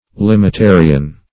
Limitarian \Lim`i*ta"ri*an\ (l[i^]m`[i^]*t[=a]"r[i^]*an)
limitarian.mp3